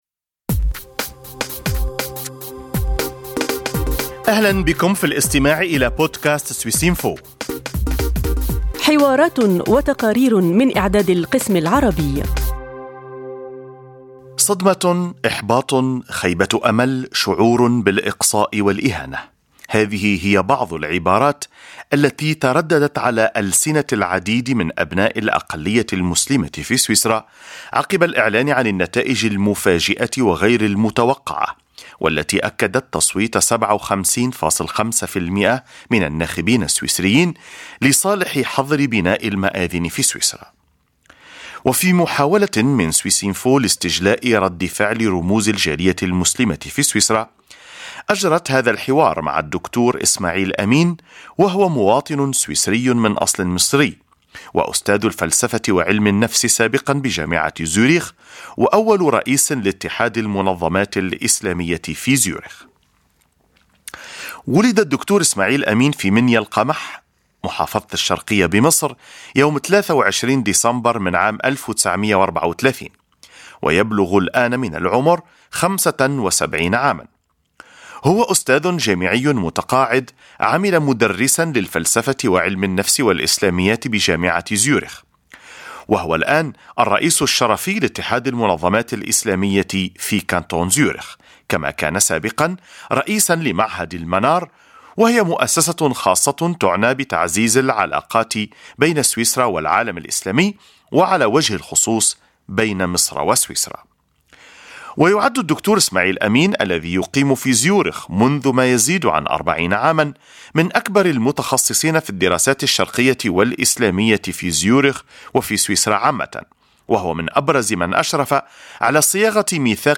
والآن ما هو المطلوب من الجالية المسلمة؟ حديث